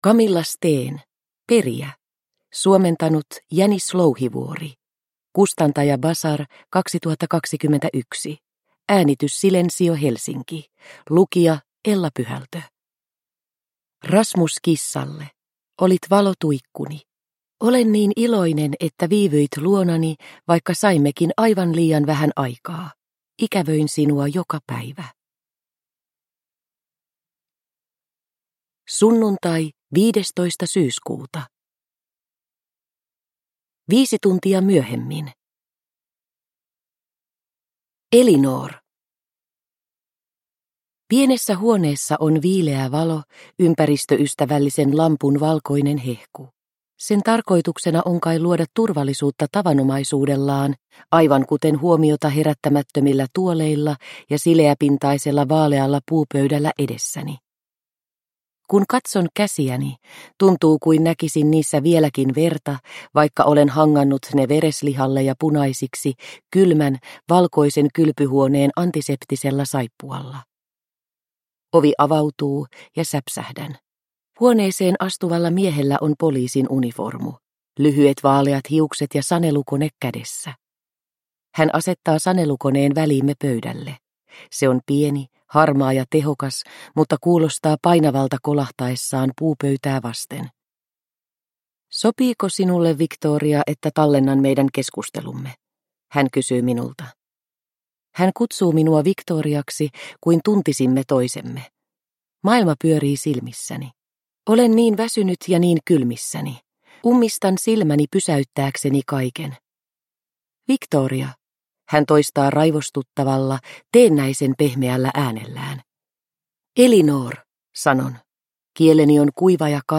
Perijä – Ljudbok – Laddas ner